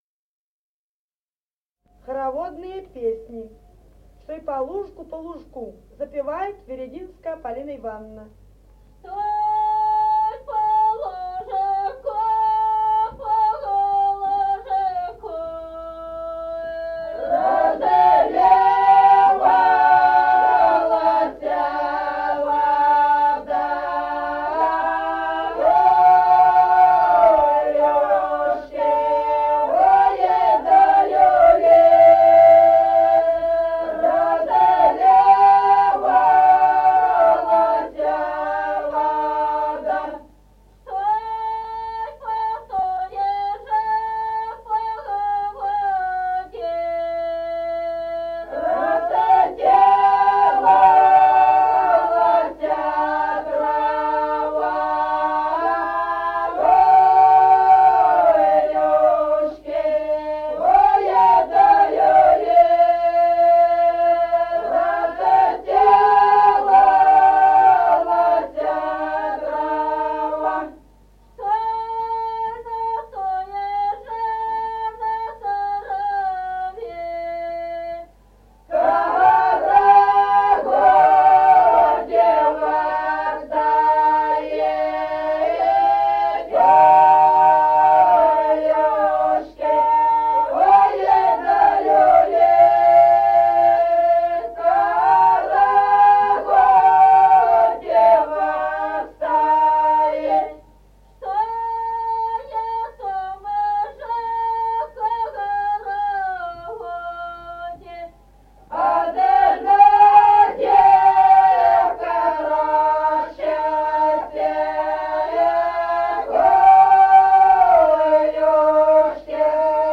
Народные песни Стародубского района «Чтой по лужку», карагодная.